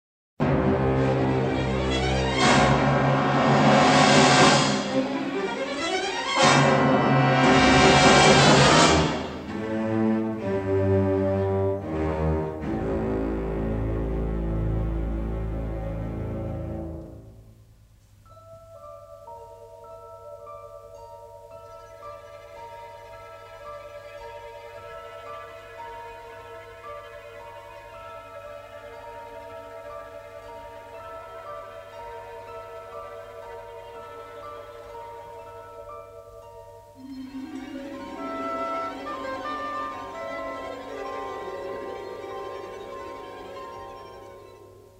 The complete symphonic score is presented in stereo